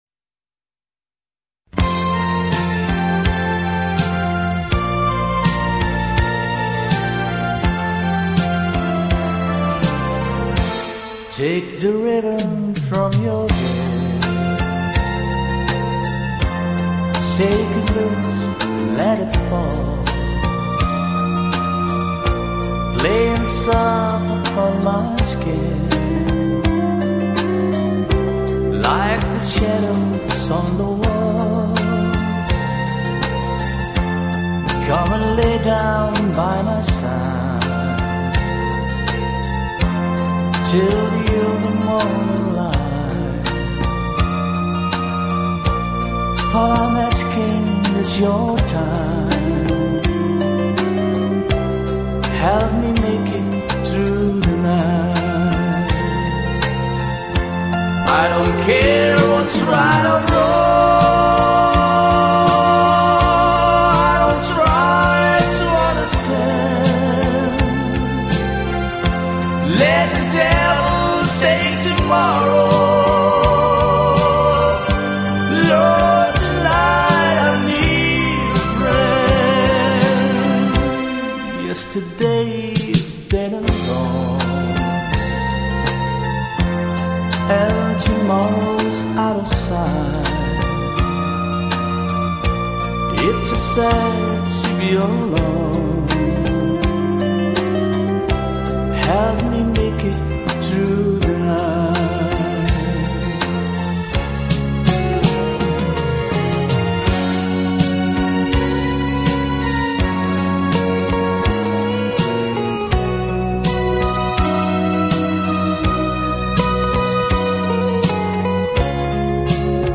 美国乡村音乐历史